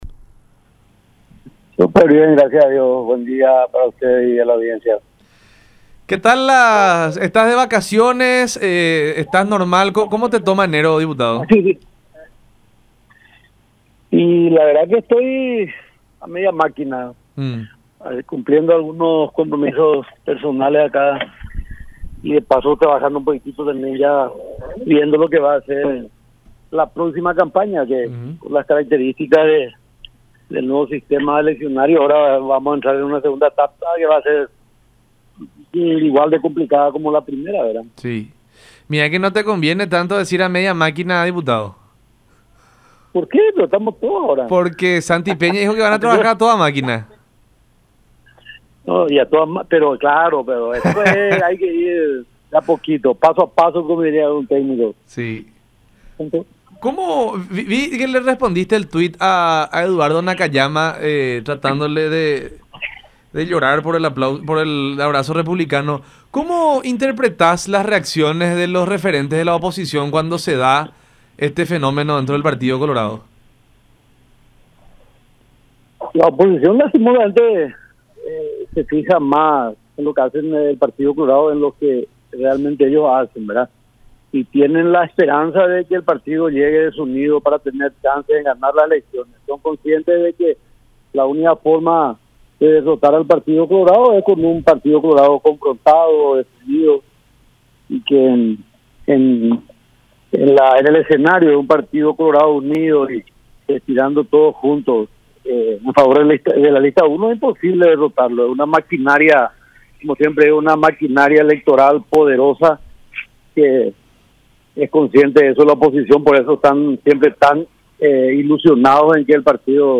“Este hecho de que el derrotado acepte los resultados ante el ganador y se acople es lo lógico en nuestro partido. Es normal que las internas sean agresivas por las características propias del Partido Colorado, que es un partido de gran arraigo popular”, dijo Harms en conversación con Buenas Tardes La Unión por Unión TV y radio La Unión.
22-WALTER-HARMS.mp3